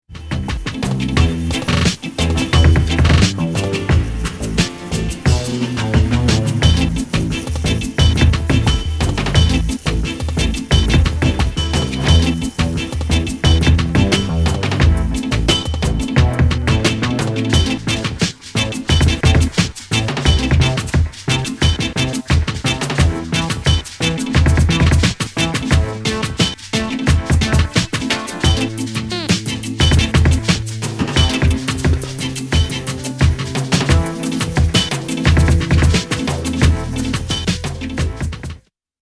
大ネタをザクっと使い、Bっぽさを前面に出した攻撃的なフリーキー・ビート、
高音で鳴るエレピもウェッサイ気分なクセになる1品。